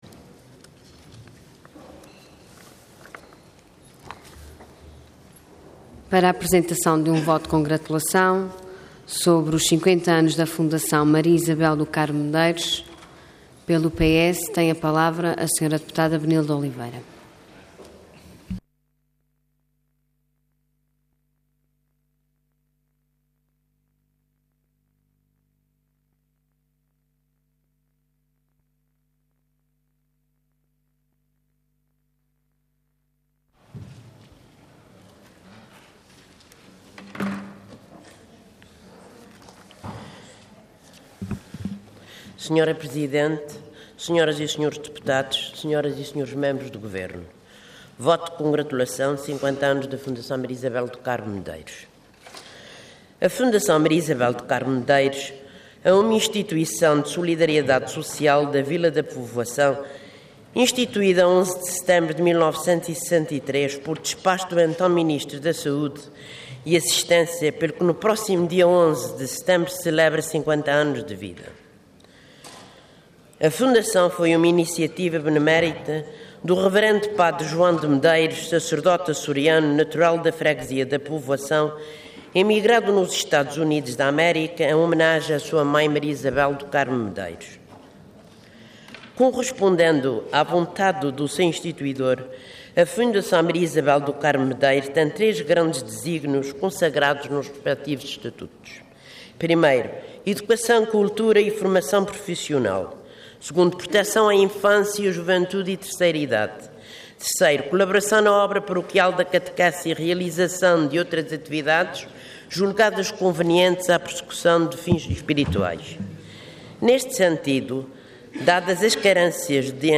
Intervenção Voto de Congratulação Orador Benilde Oliveira Cargo Deputada Entidade PS